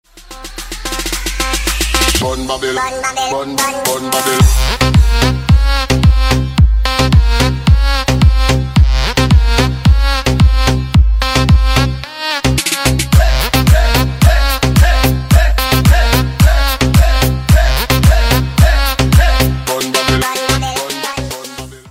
мужской голос
восточные мотивы
dance
EDM
club
качающие
забавный голос
house
electro